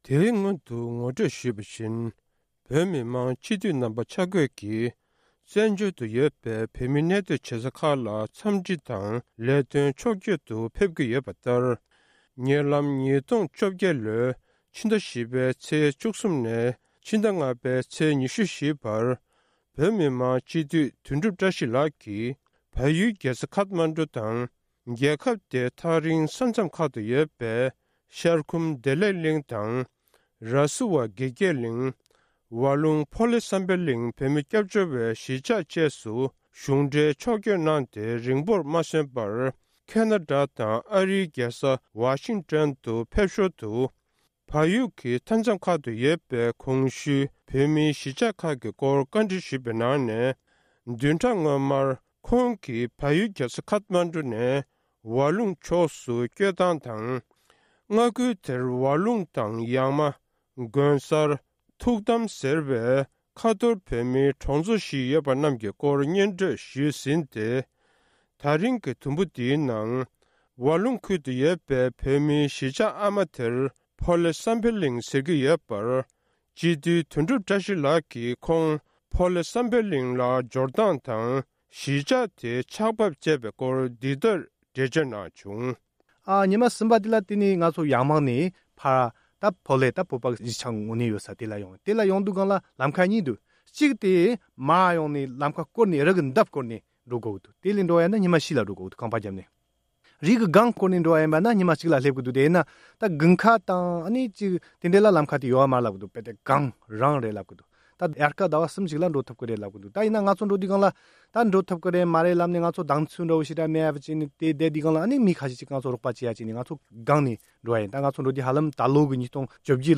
བལ་ཡུལ་ཝ་ལུང་ཁུལ་གྱི་བོད་མིའི་གནས་སྟངས་༢། སྒྲ་ལྡན་གསར་འགྱུར།